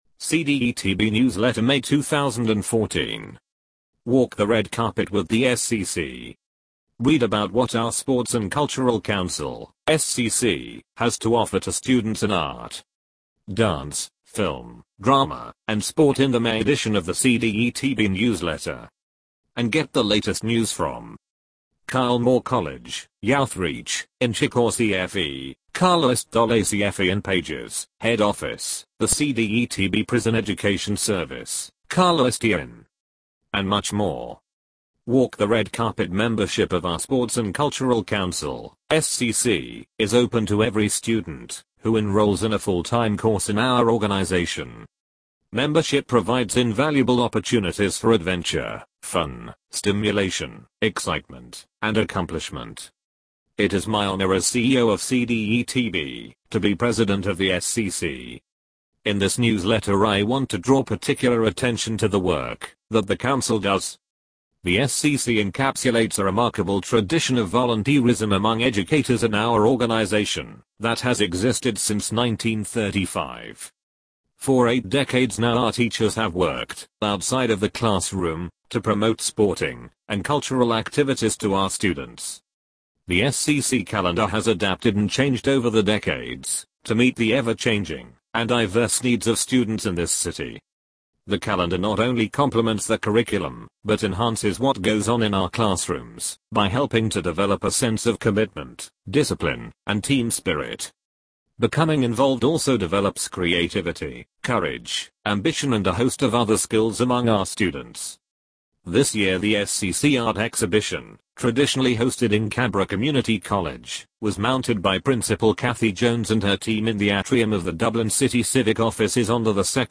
Formats: - PDF - [ Download ] – MS Word – Large Print Version - [ Download ] - Audio – MP3 Spoken Word [ Download ]